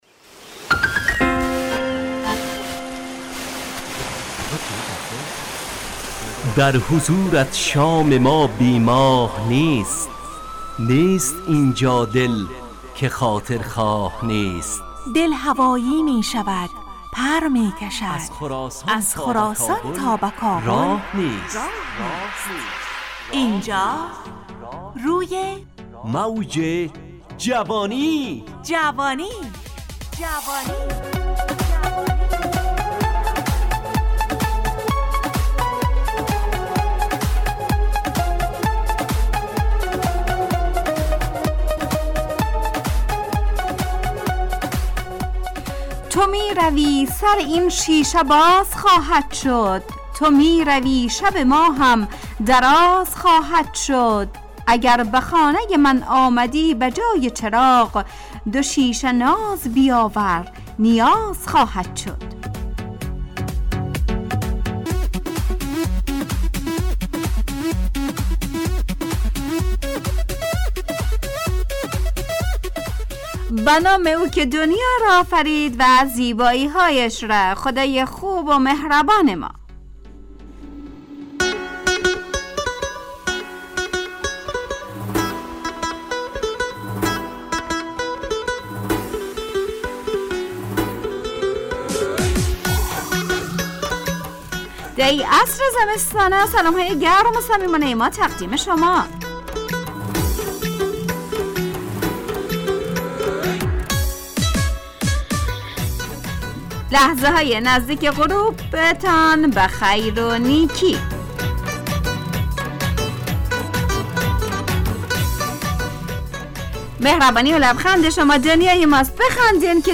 روی موج جوانی، برنامه شادو عصرانه رادیودری.
همراه با ترانه و موسیقی مدت برنامه 55 دقیقه . بحث محوری این هفته (دنیا) تهیه کننده